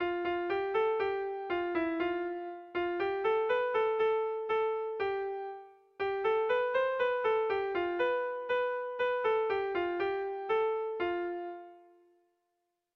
ABD